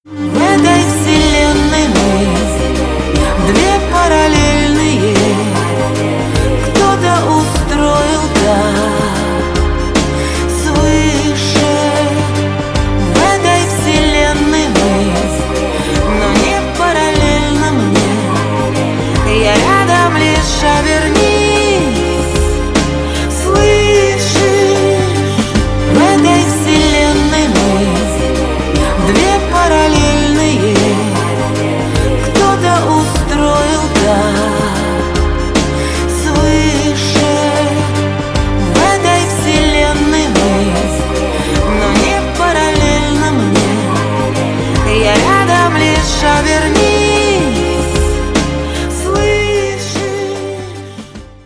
• Качество: 320, Stereo
красивые
грустные
vocal